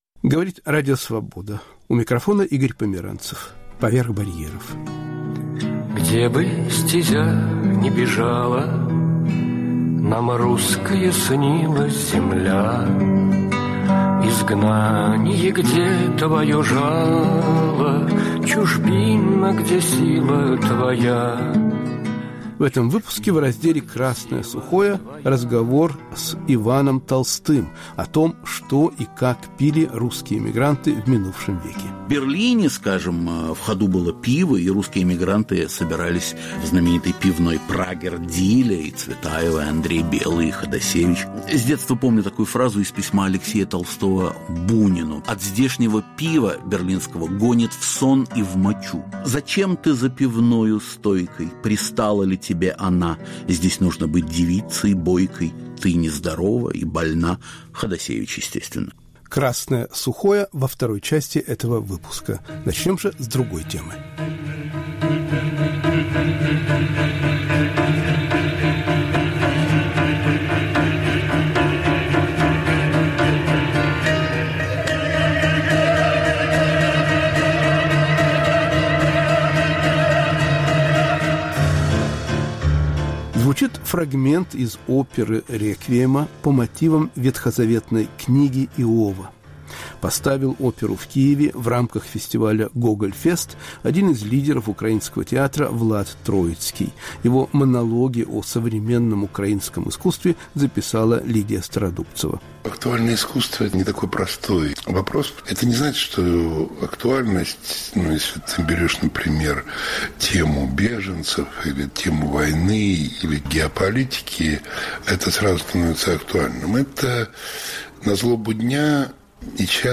Разговор с киевским театральным режиссёром Владом Троицким.*** «Документальное кино».